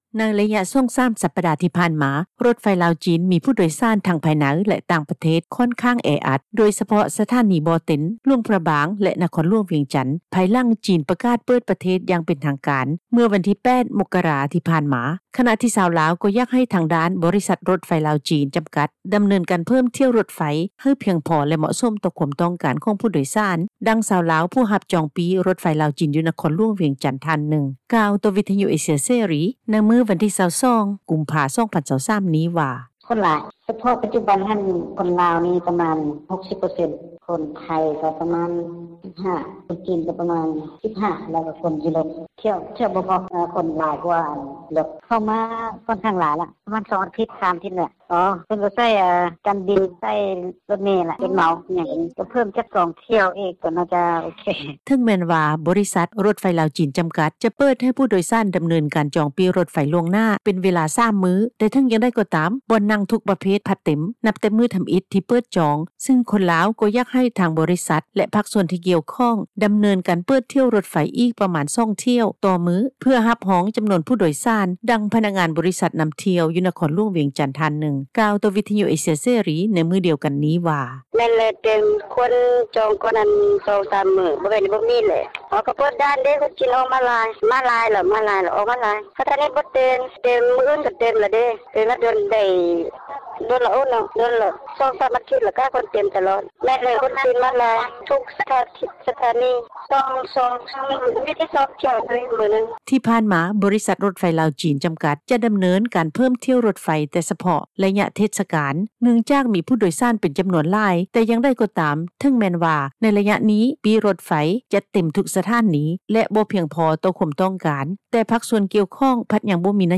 ດັ່ງ ພນັກງານບໍຣິສັດນໍາທ່ຽວ ຢູ່ນະຄອນຫລວງວຽງຈັນ ທ່ານນຶ່ງ ກ່າວຕໍ່ວິທຍຸເອເຊັຽ ເສຣີ ໃນມື້ດຽວກັນນີ້ວ່າ:
ດັ່ງ ຜູ້ໂດຍສານຣົຖໄຟ ລາວ-ຈີນ ເປັນປະຈໍາ ນາງນຶ່ງກ່າວວ່າ:
ດັ່ງ ຄົນຂັບຣົຖໂດຍສານ ເສັ້ນທາງນະຄອນຫລວງວຽງຈັນ ຫາ ນະຄອນຫລວງພຣະບາງ ທ່ານນຶ່ງກ່າວວ່າ: